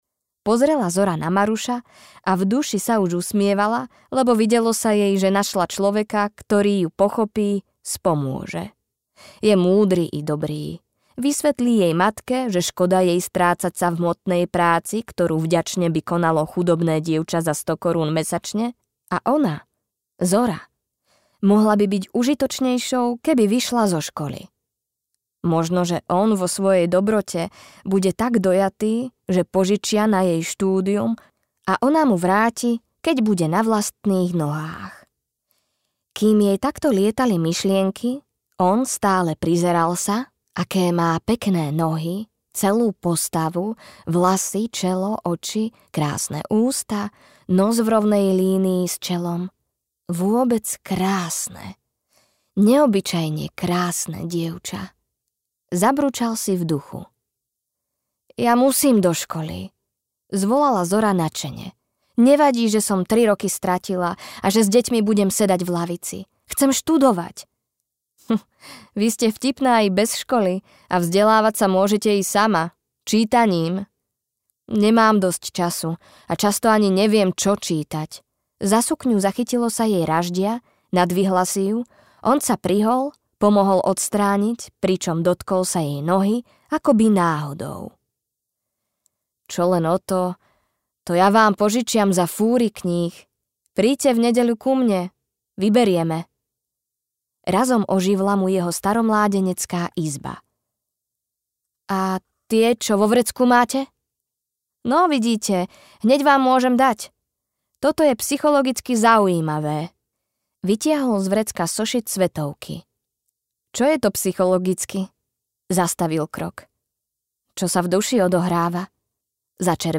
Vlny duše audiokniha
Ukázka z knihy